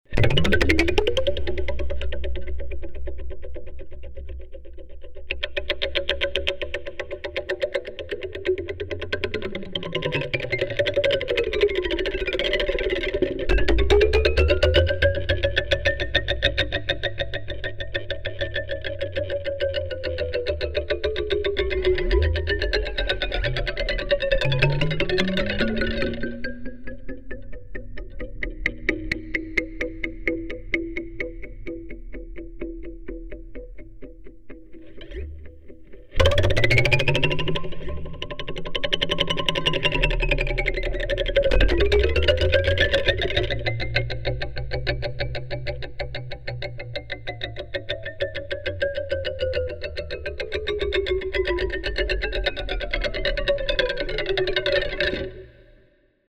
When you pull the weight to one side and release, the weighted rod slowly works its way down though the hole as it sways back and forth, dropping a tiny bit with each swing. There’s a sound as the rod hits against the side of the hole with each sway, and the sound changes as the relative lengths of the portions of the rod above and below the bar change.